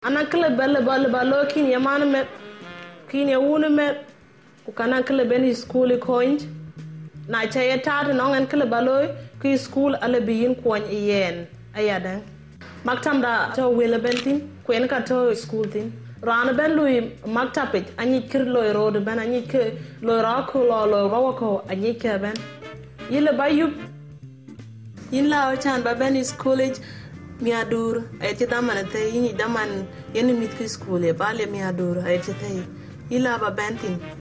I also hear a lot of ɯ and retroflex consonants.
– The background music in the room (convention centre?) is American.